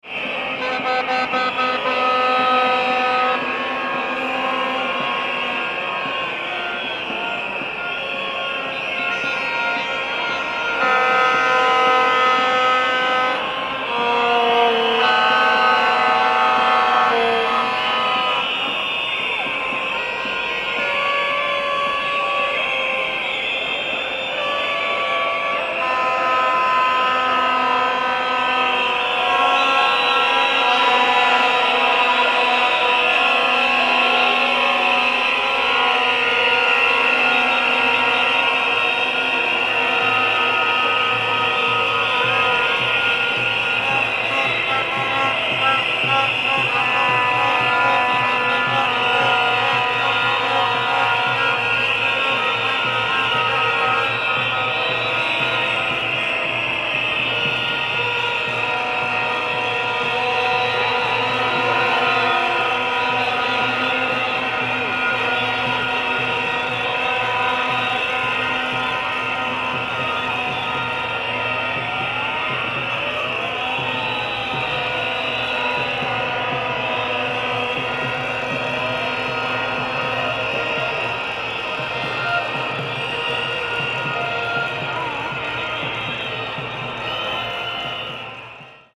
Massive Loud Protest Crowd Sound Effect
Large crowd protesting with loud noises. Political turmoil, street demonstration with shouting and noise – sound effects of civil unrest.
Human sounds.
Massive-loud-protest-crowd-sound-effect.mp3